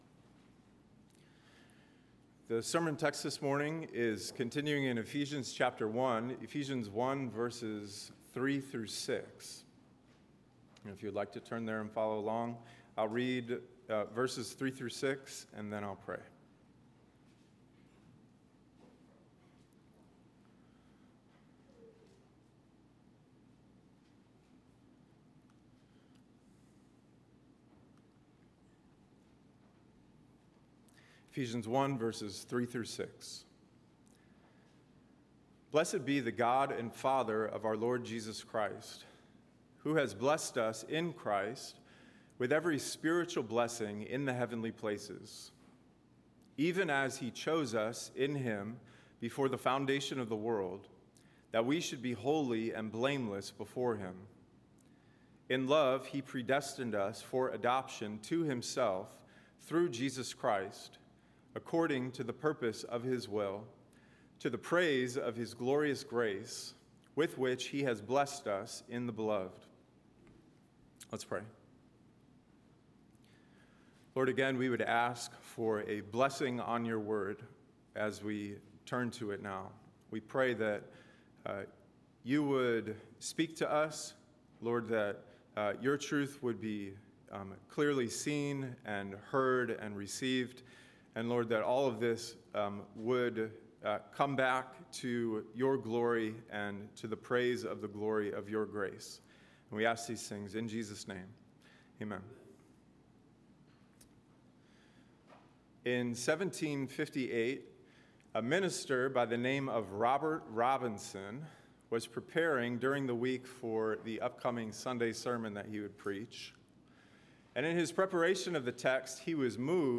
A message from the series "Ephesians 2025."